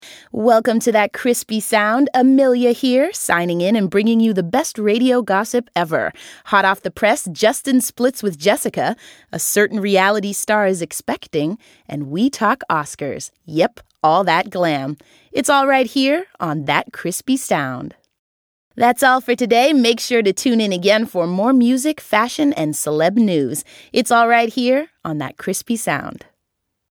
» Stemmeprøver